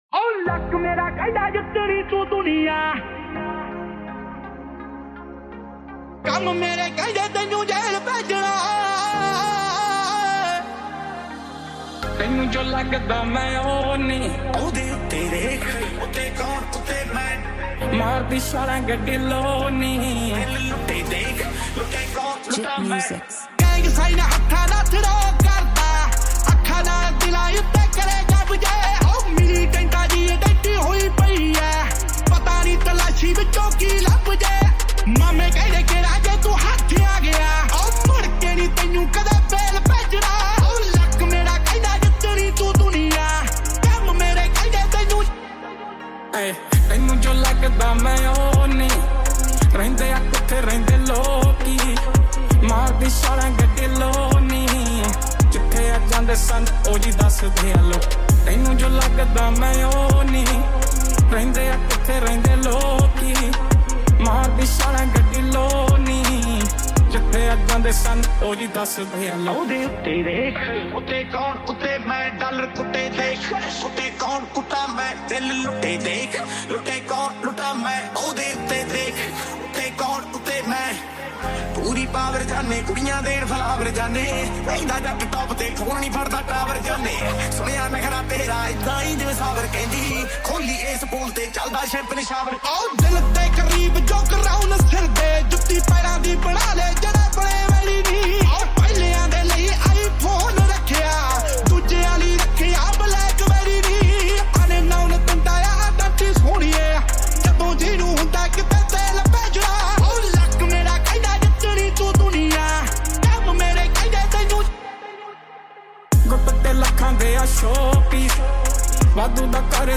MASHUP SONG